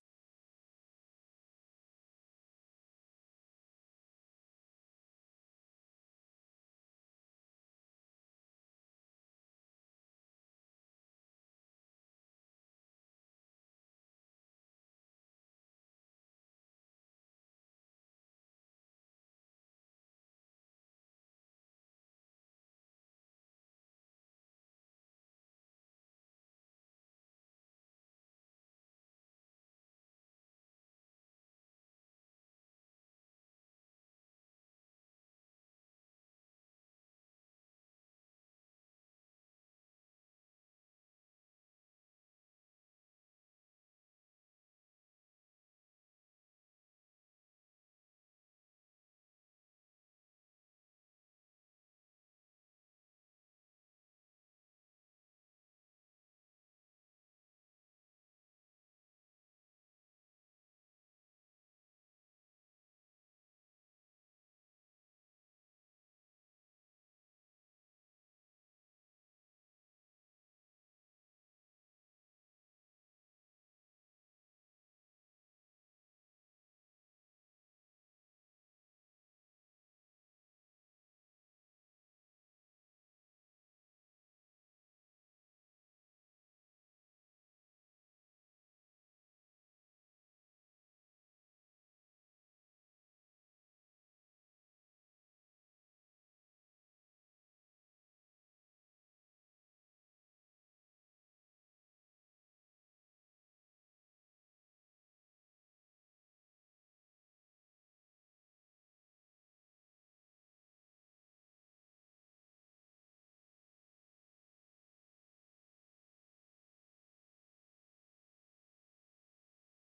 Experimental Epic 03:40